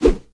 handswing3.ogg